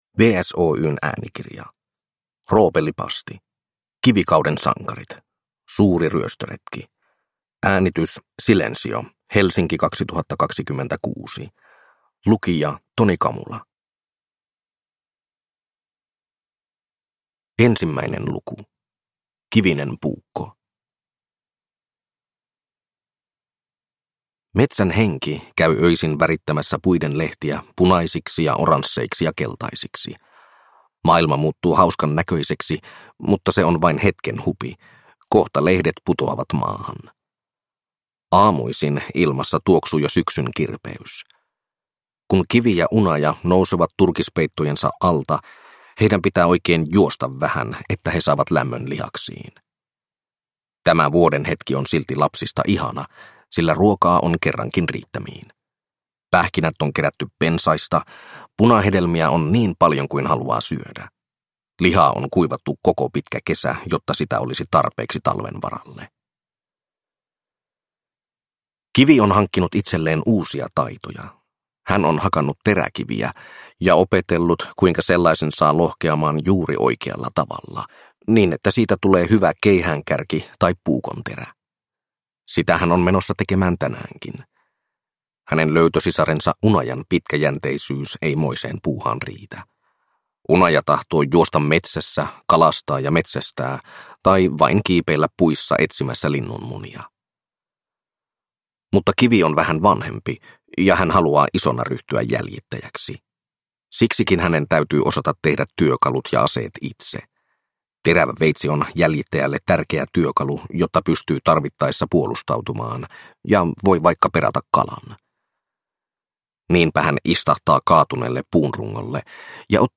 Kivikauden sankarit - Suuri ryöstöretki – Ljudbok